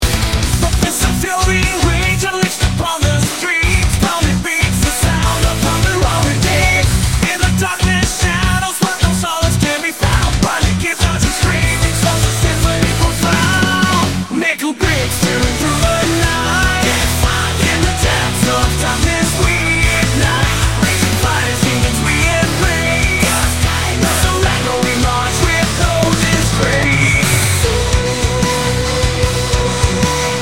ところで、なんだよ、この曲☝、LAメタルのダメな奴みたいだなあ。曲が軽くて、心をひとつも打たない。「にくばいつ」ときっちり発音しろよ。